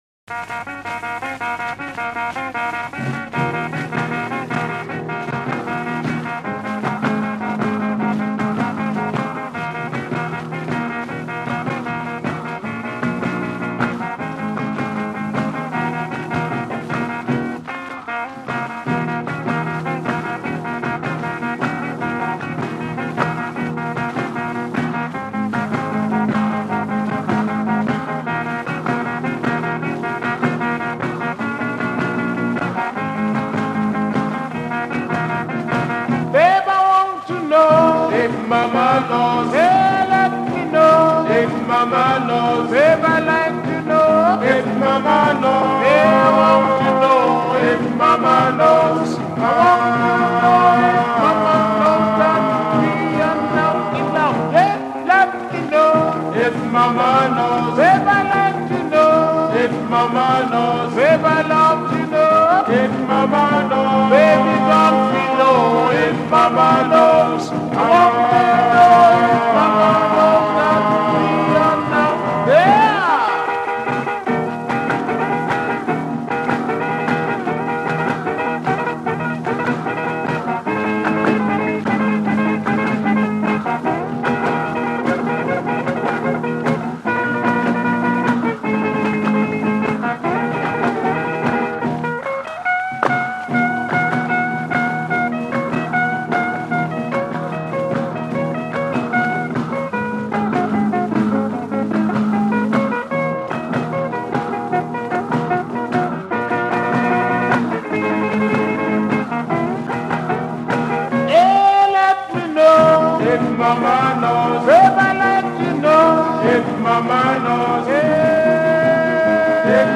guitar solo
rhythm guitar
singer
drums